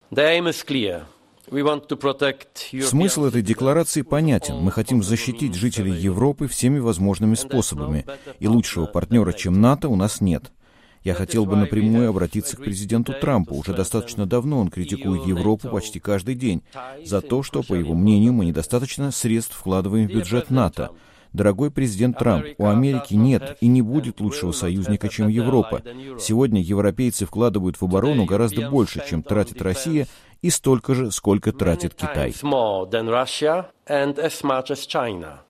Дональд Туск выступает на пресс-конференции в Брюсселе